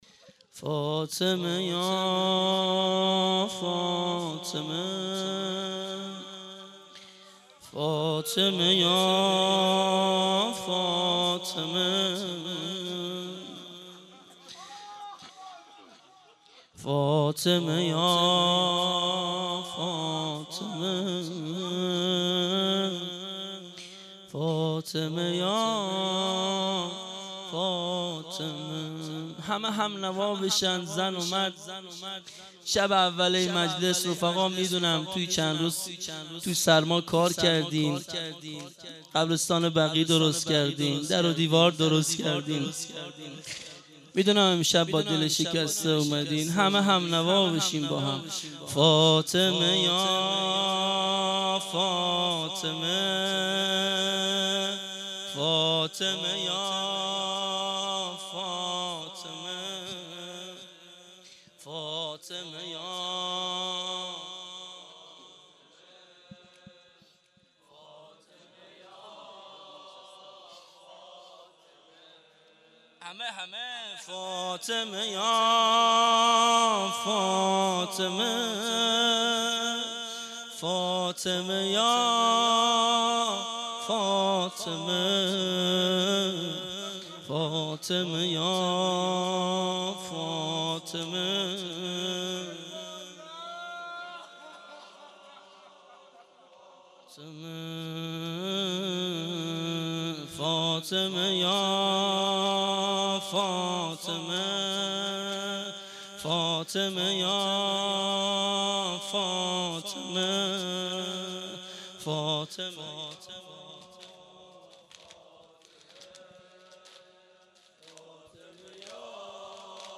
فاطمیه97- مجمع دلسوختگان بقیع- شب اول- زمینه